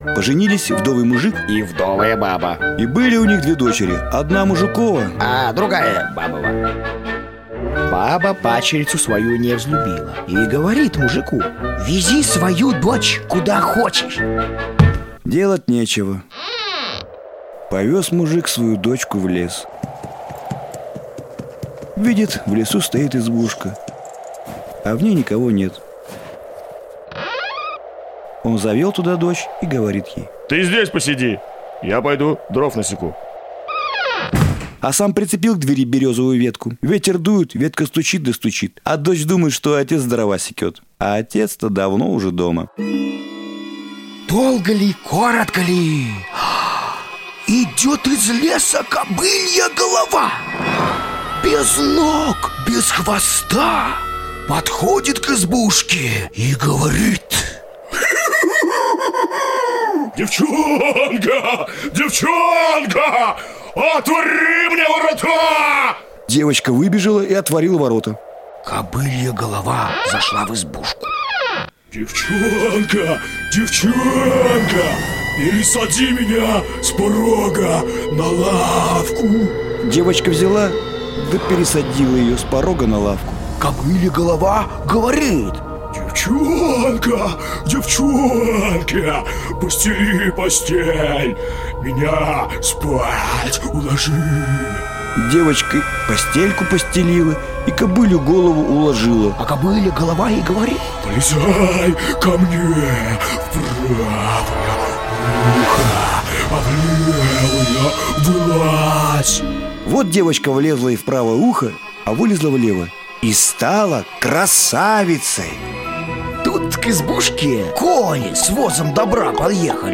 Рок
Жанр: Жанры / Рок